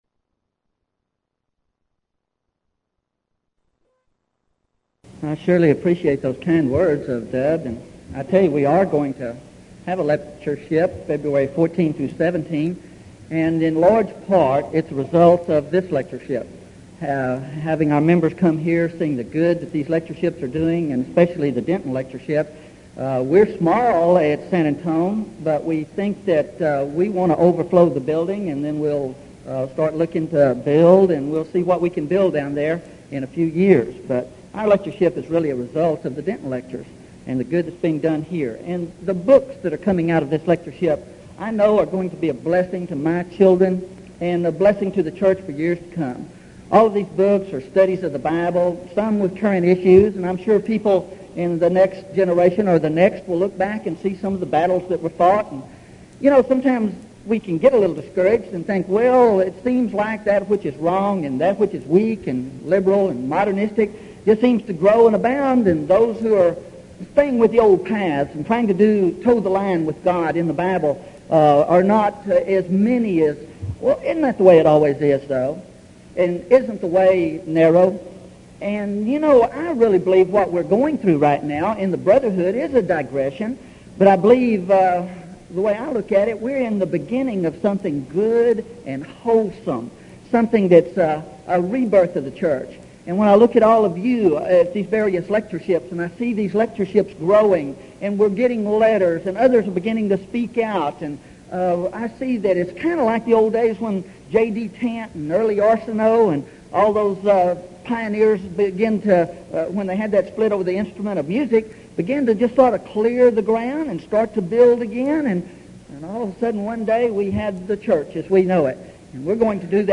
Event: 1986 Denton Lectures
lecture